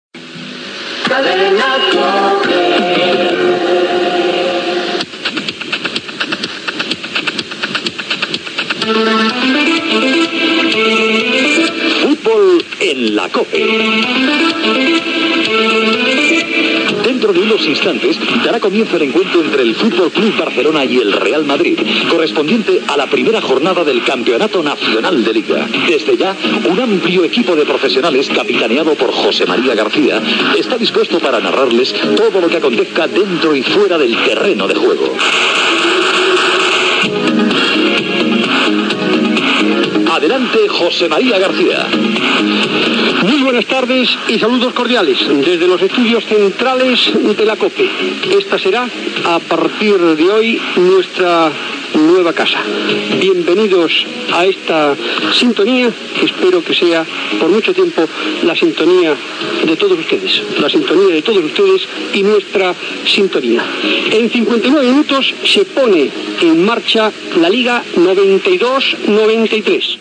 Indicatiu de la cadena, careta del programa, salutació en el debut a la COPE de José María García. Inici de la prèvia a la transmissió del partit de futbol de la lliga masculina entre el Futbol Club Barcelona i el Real Madrid
Esportiu